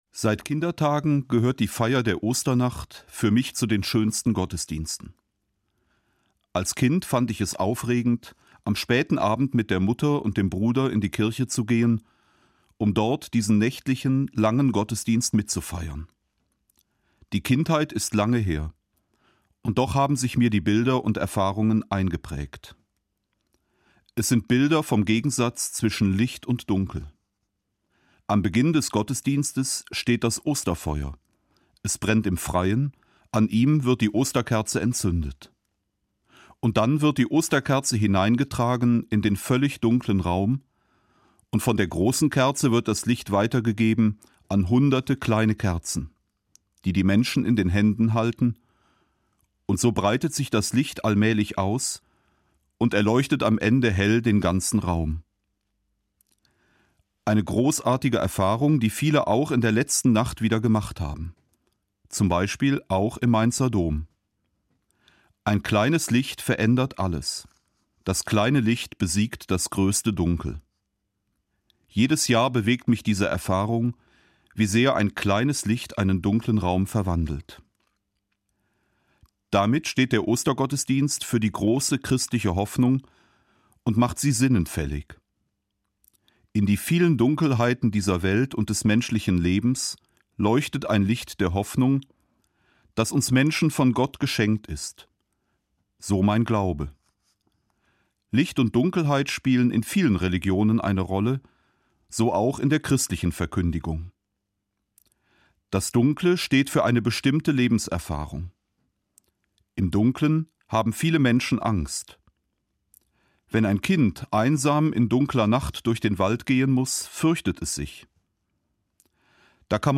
Eine Sendung von Peter Kohlgraf, Bischof von Mainz